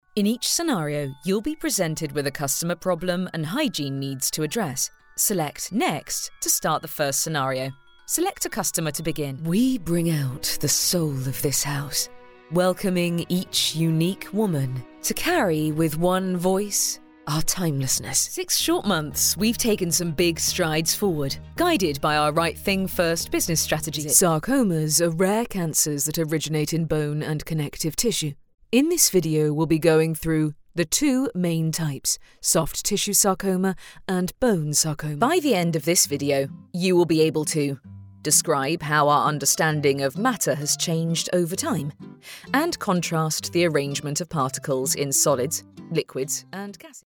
Female
English (British)
My natural vocal tone is sincere, friendly and direct with a clarity and warmth.
Corporate
Words that describe my voice are Warm, Confident, Friendly.